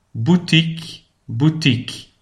PRONONCIATION